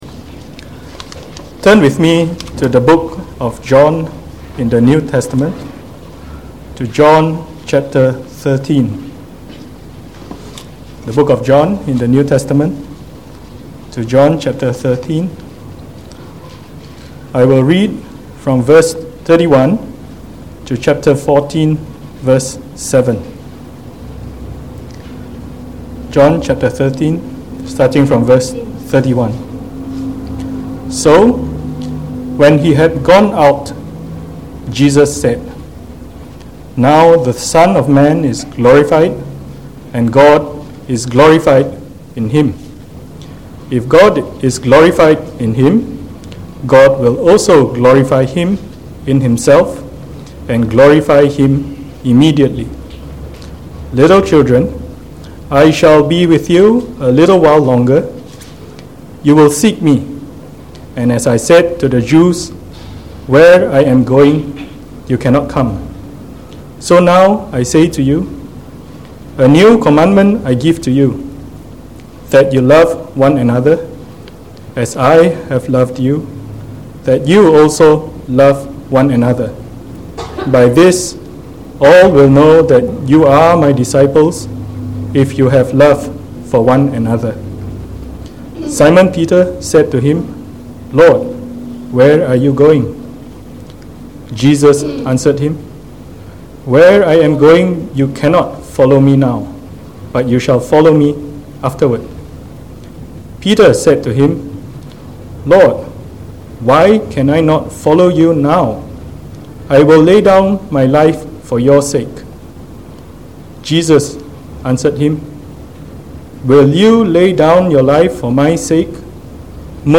Preached on the 7th April 2019. From our series on the Gospel of John delivered in the Evening Service